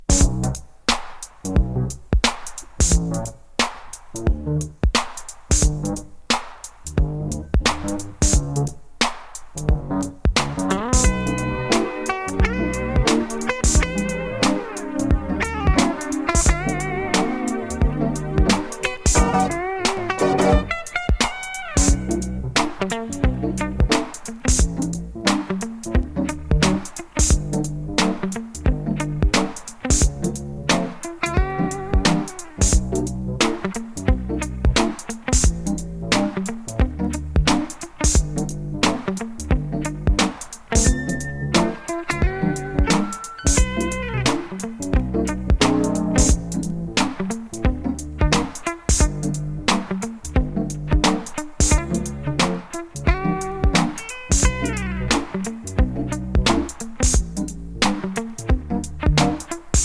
ロービート・アーバンチューン